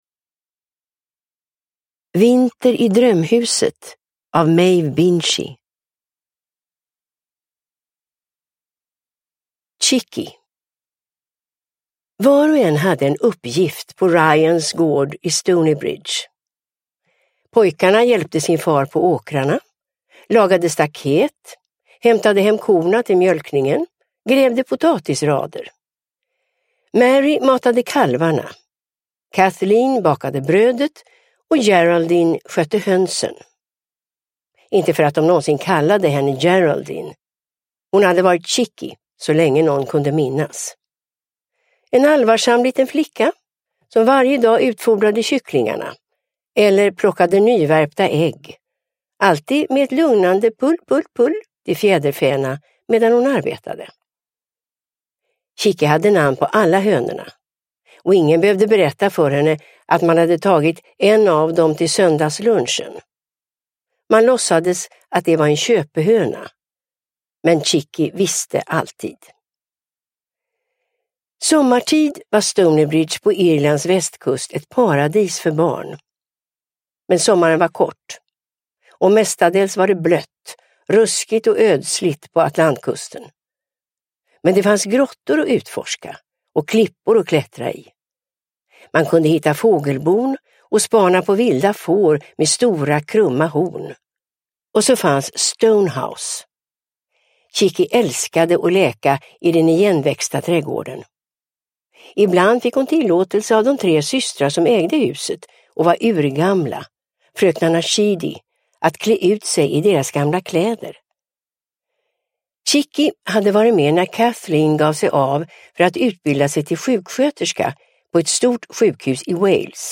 Vinter i drömhuset – Ljudbok
Uppläsare: Irene Lindh